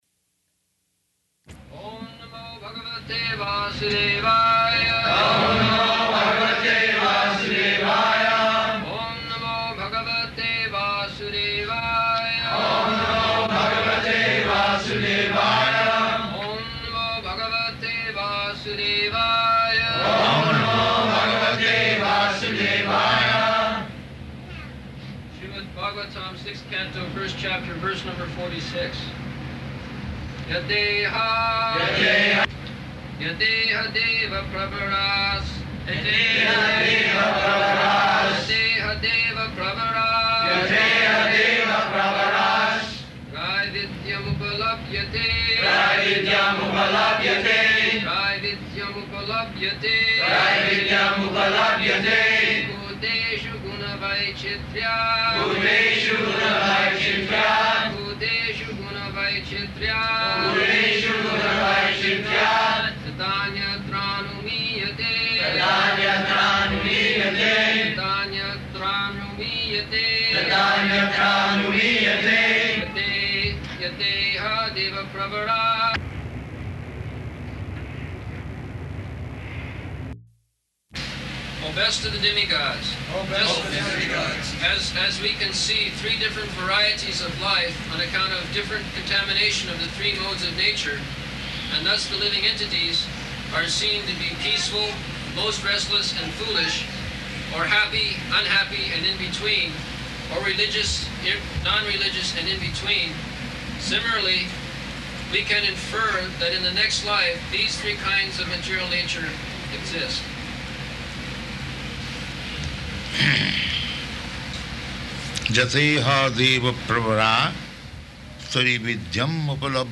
-- Type: Srimad-Bhagavatam Dated: July 27th 1975 Location: San Diego Audio file
[Prabhupāda and devotees repeat] Śrīmad-Bhāgavatam, Sixth Canto, First Chapter, verse number 46. [leads devotees in chanting verse, etc.]